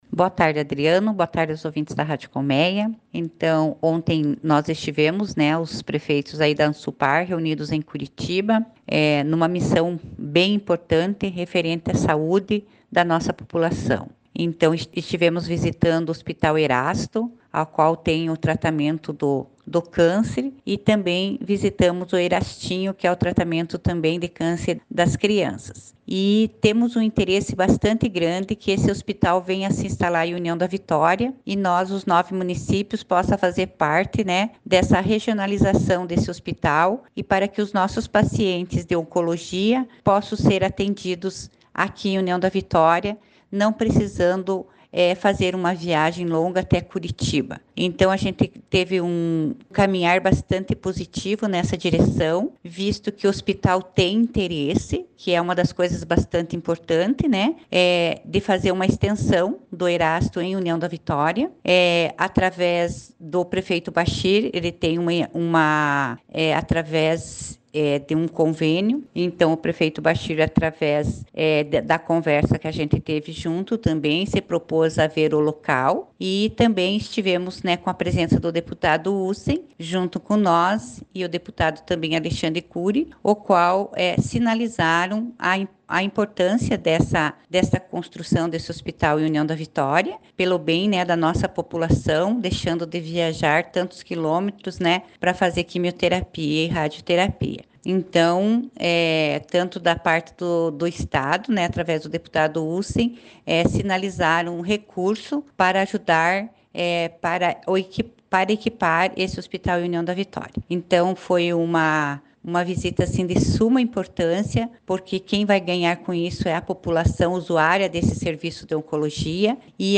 Acompanhe a fala dos prefeitos de União da Vitória, Bachir Abbas; de General Carneiro, Joel Ferreira; de Cruz Machado, Antonio Szaykowski; e também da prefeita de Porto Vitória, Marisa Ilkiu, sobre a visita.
Prefeita Marisa Ilkiu: